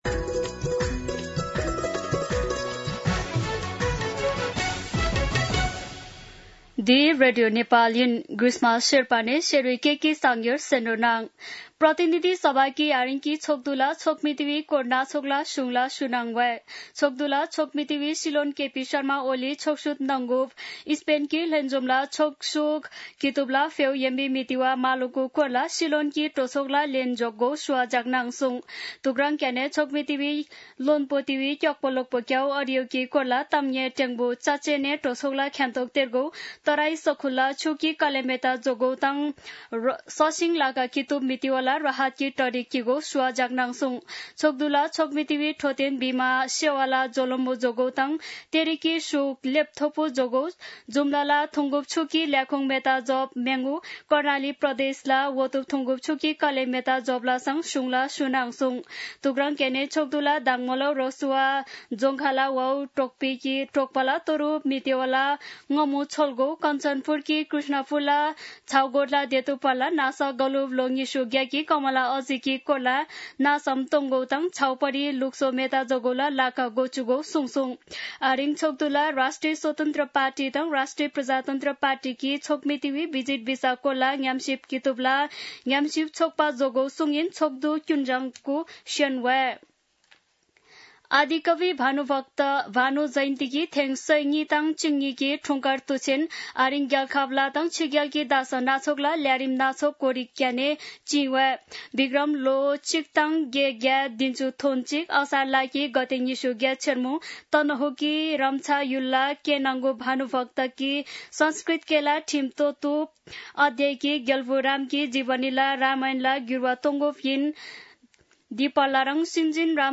शेर्पा भाषाको समाचार : २९ असार , २०८२